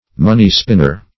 money-spinner - definition of money-spinner - synonyms, pronunciation, spelling from Free Dictionary